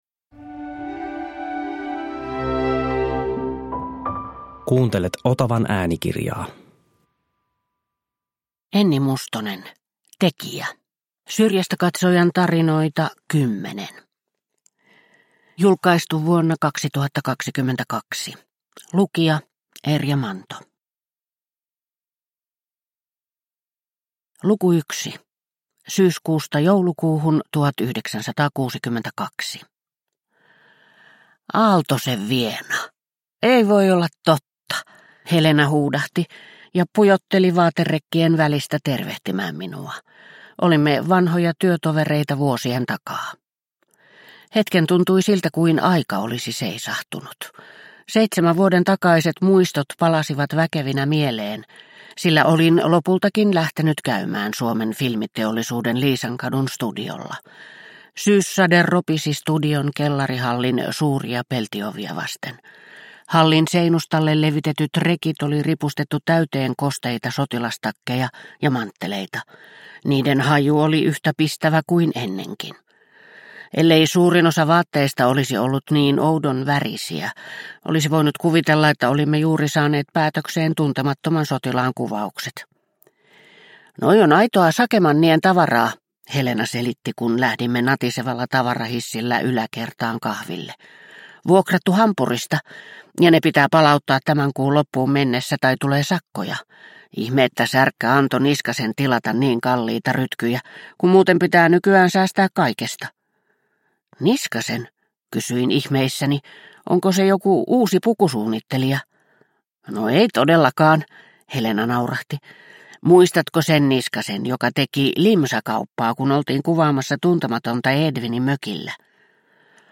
Tekijä – Ljudbok – Laddas ner